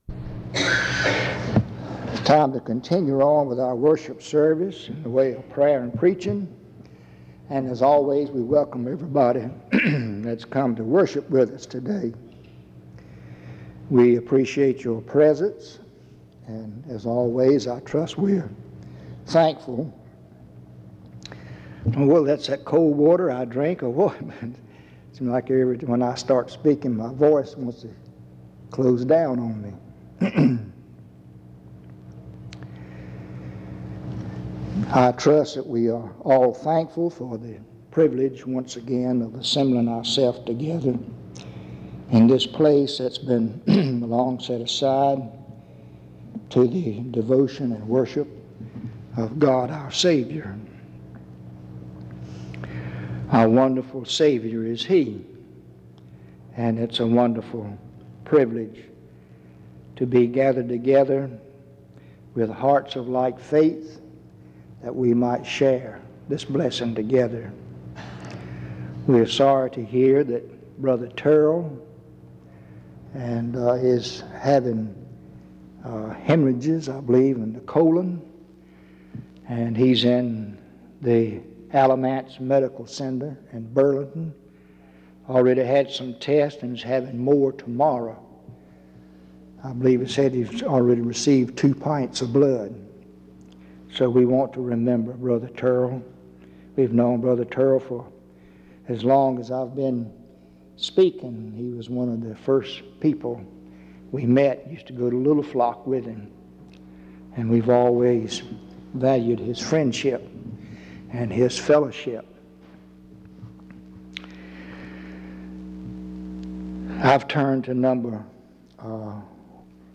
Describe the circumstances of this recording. Reidsville/Lindsey Street Primitive Baptist Church audio recordings